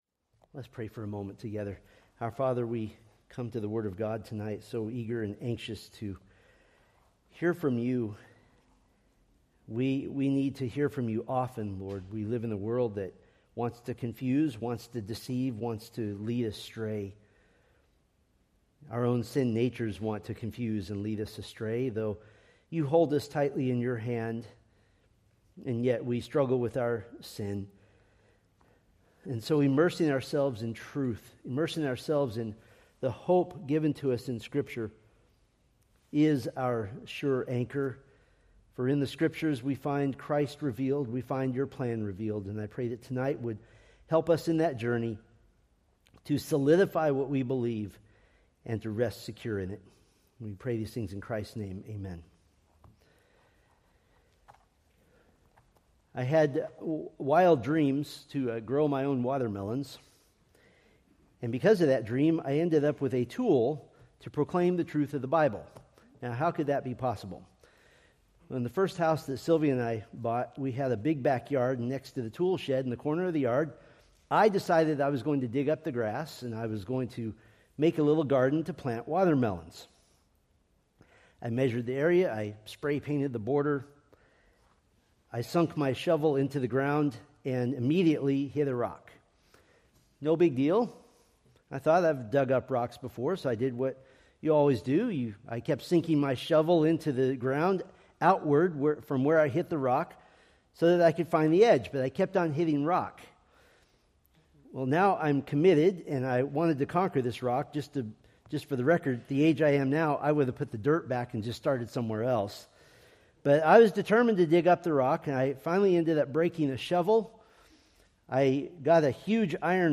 Preached February 15, 2026 from Selected Scriptures